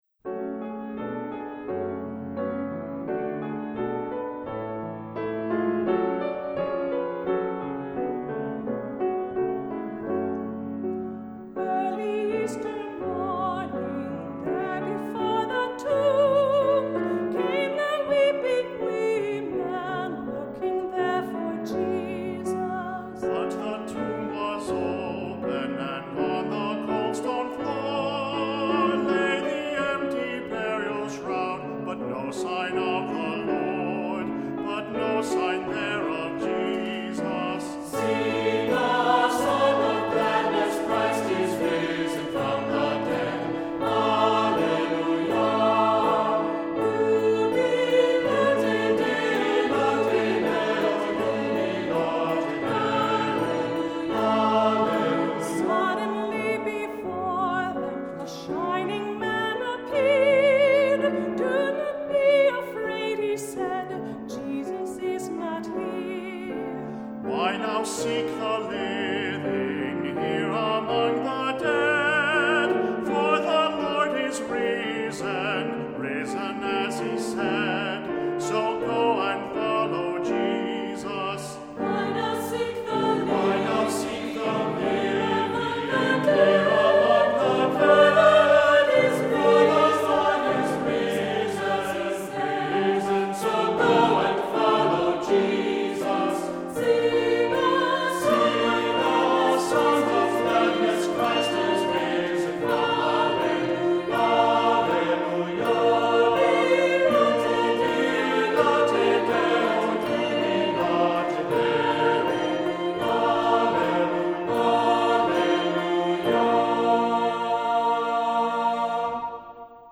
Voicing: Two-part equal